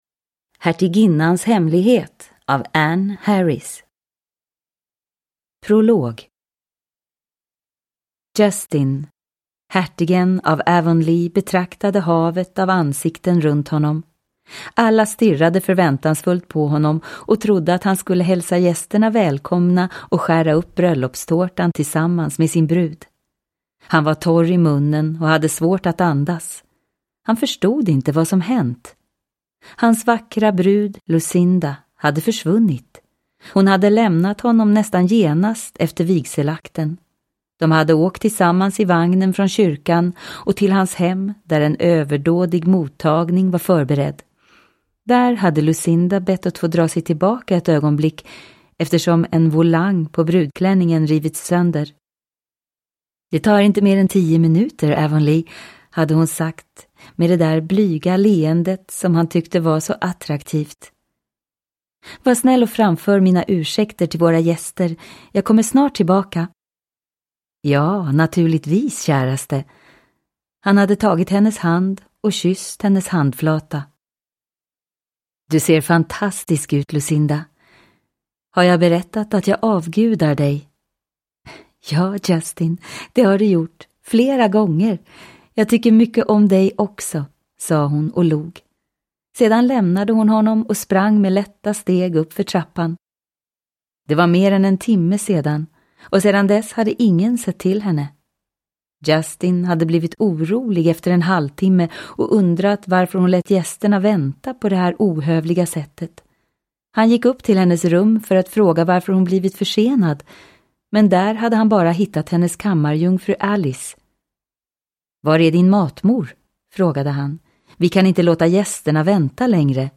Hertiginnans hemlighet – Ljudbok – Laddas ner